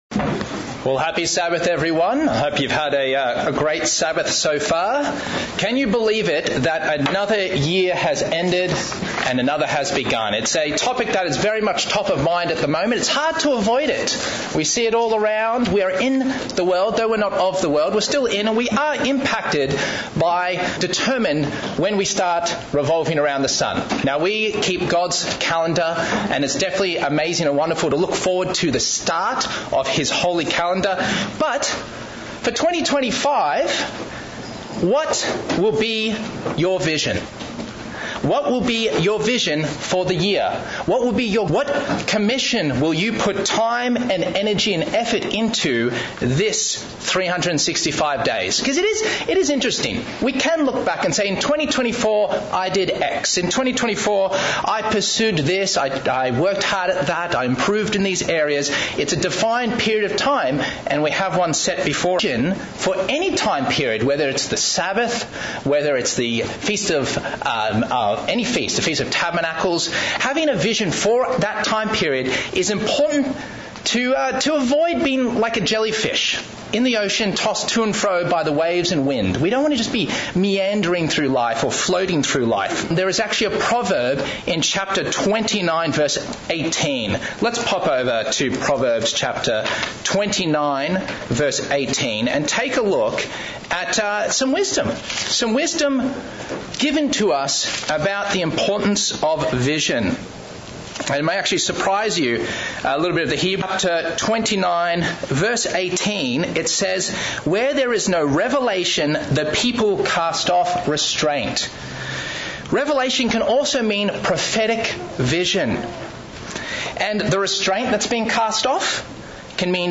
Sermons
Given in Central Illinois